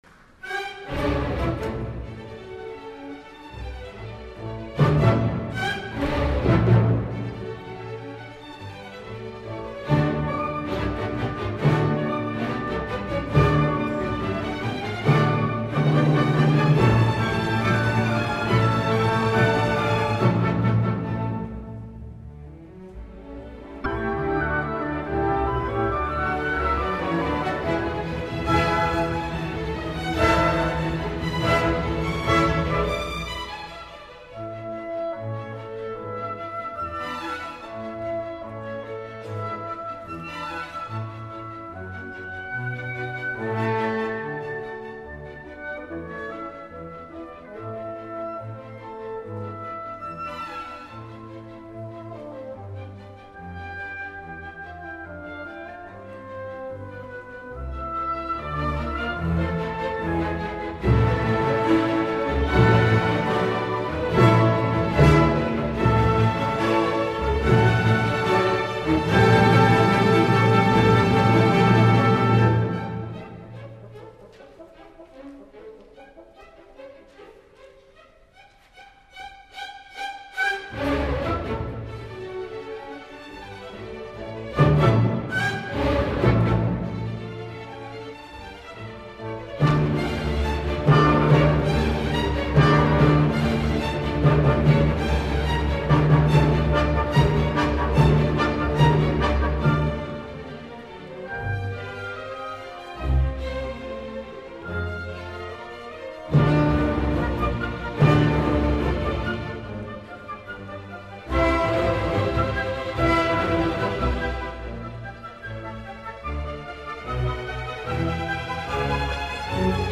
El dia 20 de juliol es va iniciar, en el marc dels PROMS, la integral de les simfonies de L.V.Beethoven a càrrec de la West-Eastern Divan Orchestra dirigida per Daniel Barenboim, que es convertirà en el segon director, després de Henry Wood que ho va fer al 1942, en dirigir el cicle sencer de les simfonies en una mateixa edició del festival londinec.
Avui us porto el primer concert d’aquesta integral, el que acull les dues primeres simfonies
allegro-molto.mp3